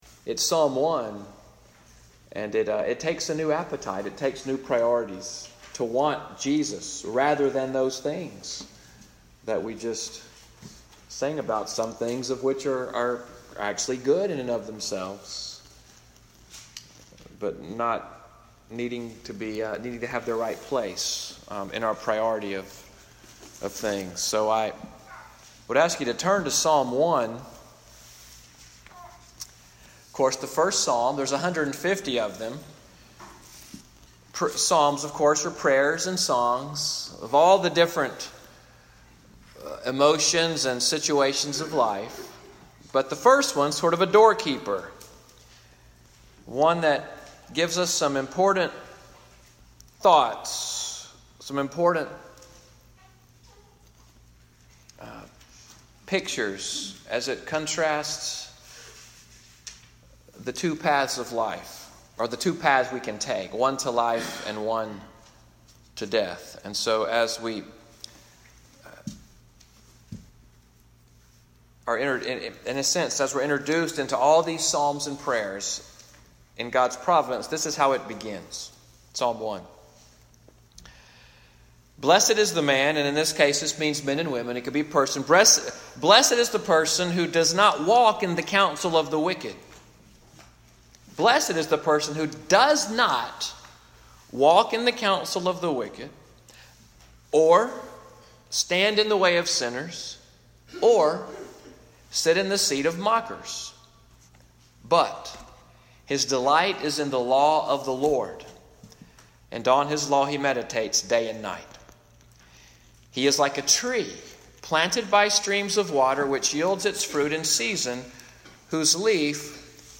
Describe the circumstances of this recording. Sermon audio from Morning Worship at Little Sandy Ridge Presbyterian Church, Fort Deposit, Alabama, September 9, 2018.